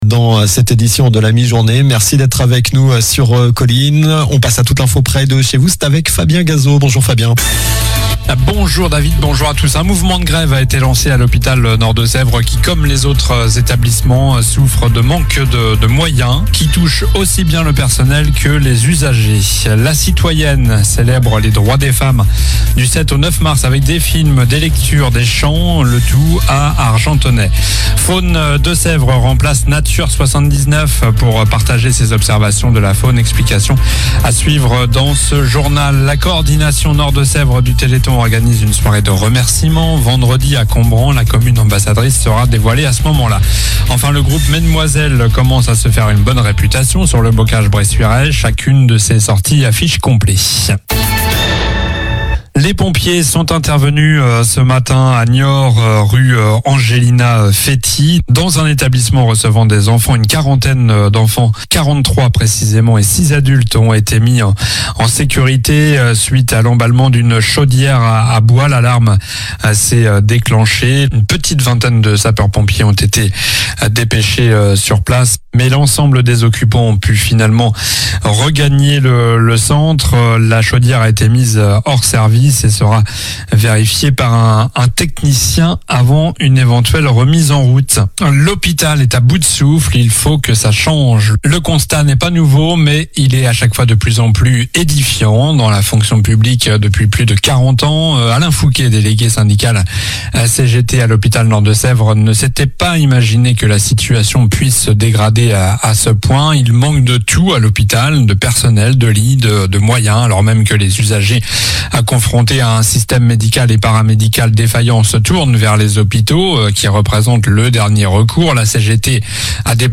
Journal du mardi 04 mars (midi)